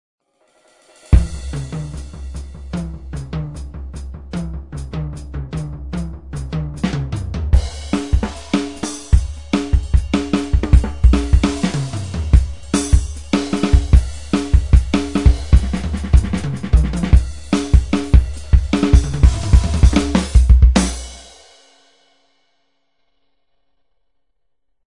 Барабаны